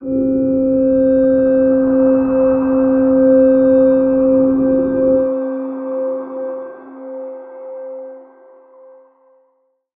G_Crystal-C5-f.wav